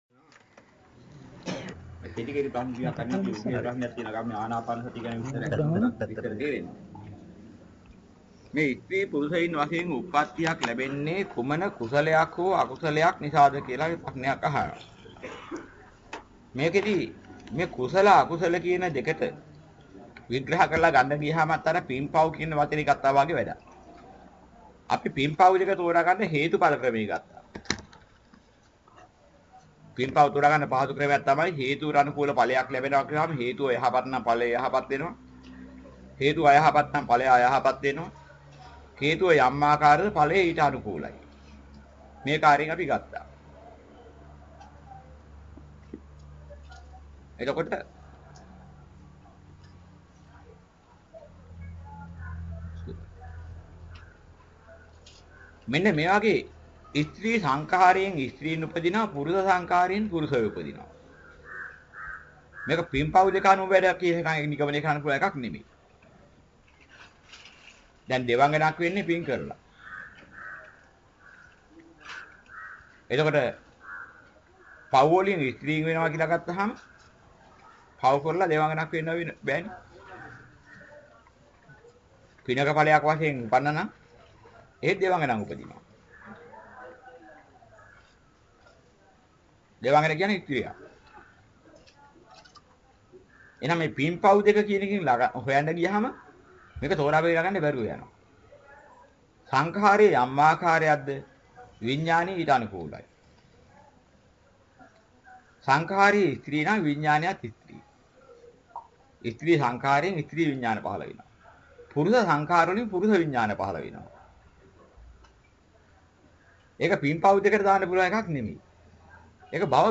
දේශනාවට සවන් දෙන්න (අහන ගමන් කියවන්න)